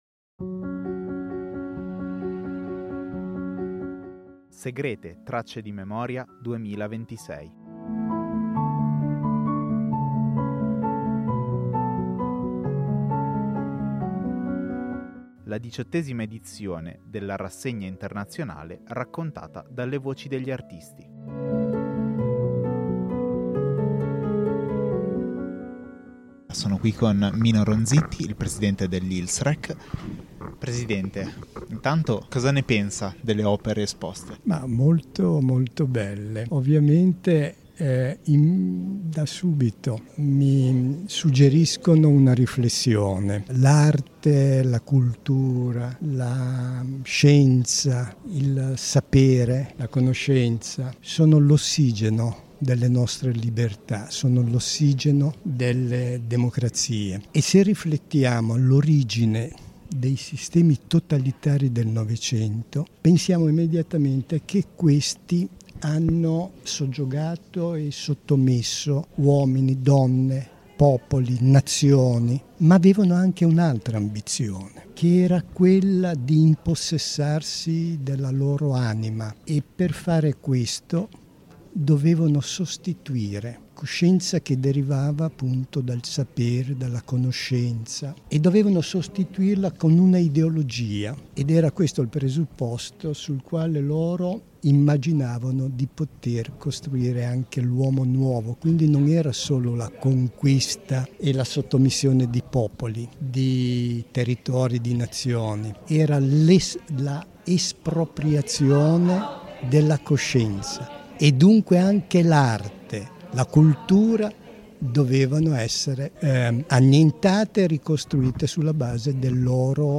Intervista